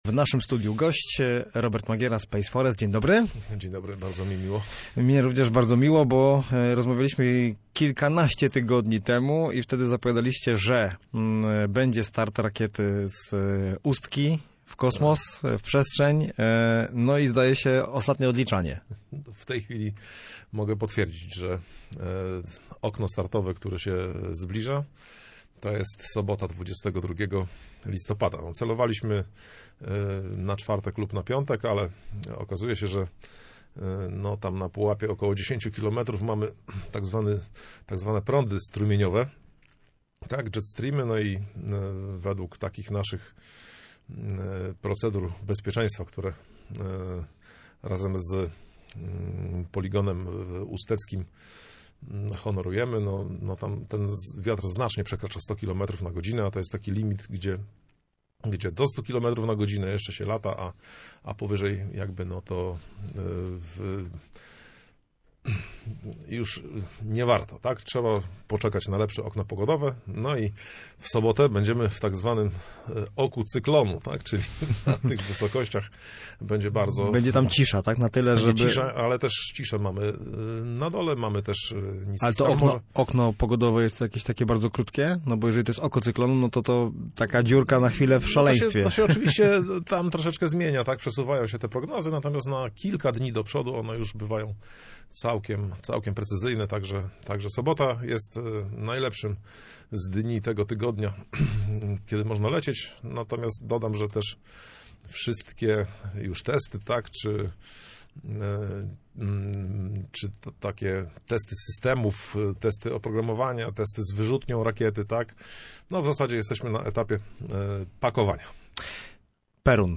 był gościem miejskiego programu Radia Gdańsk Studio Słupsk 102 FM. Rozmowy można posłuchać tutaj: https